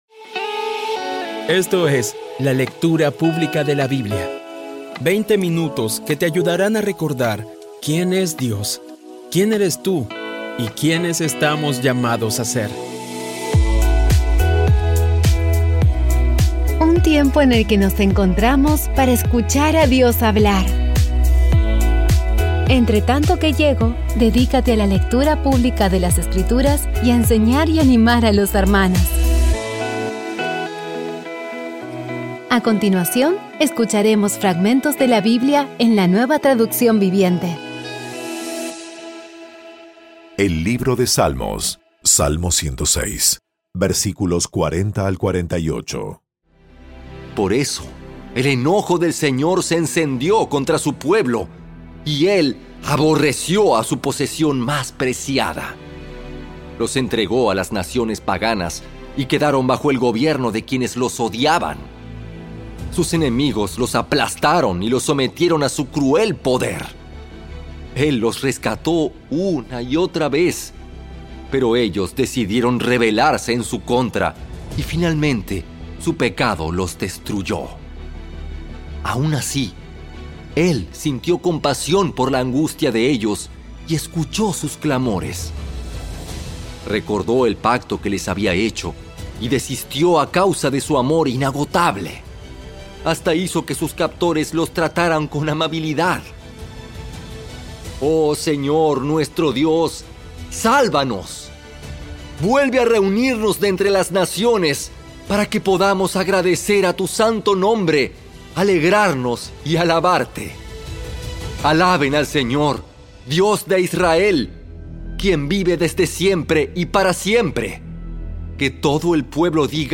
Audio Biblia Dramatizada Episodio 267
Poco a poco y con las maravillosas voces actuadas de los protagonistas vas degustando las palabras de esa guía que Dios nos dio.